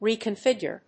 発音記号・読み方
/ˌrikʌˈnfɪgjɝ(米国英語), ˌri:kʌˈnfɪgjɜ:(英国英語)/